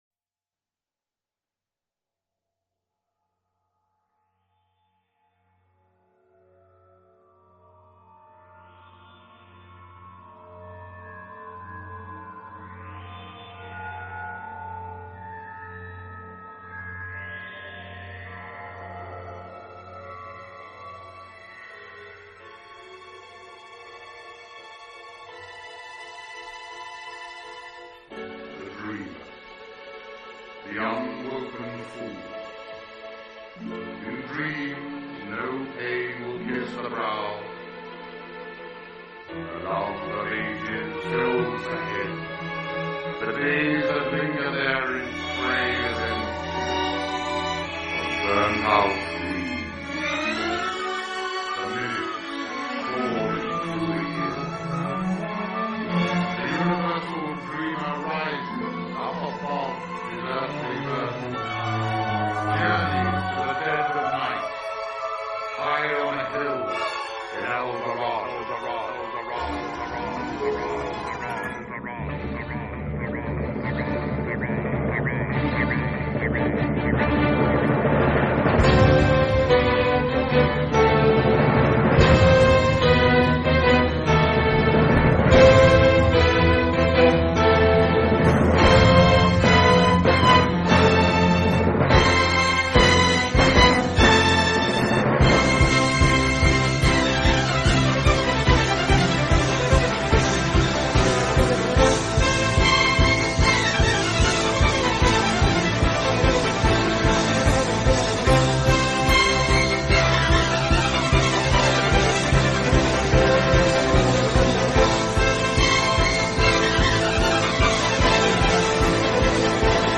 Progressive Rock, Symphonic Rock